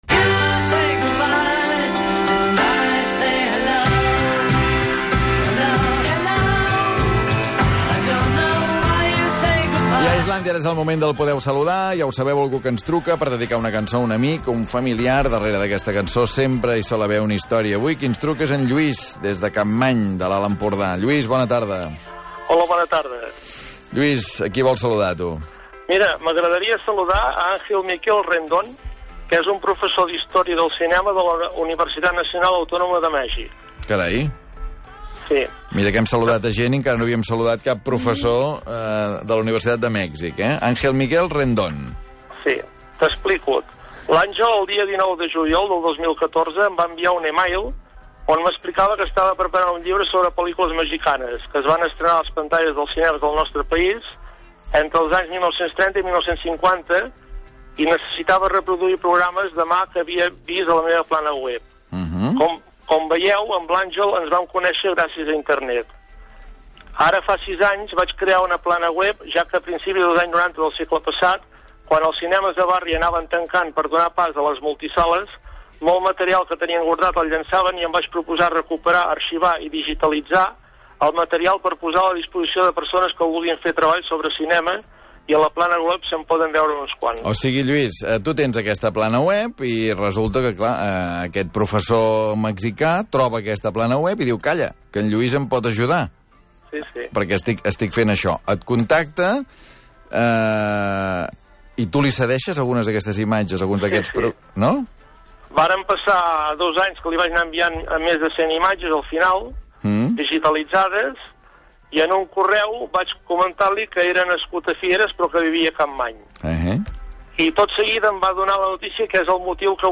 Albert Om presentaba a diario un programa en la emisora RAC-1 Este programa llevaba por título Islandia y existía un apartado donde los oyentes podían saludar y dedicar una canción a la persona que deseaban.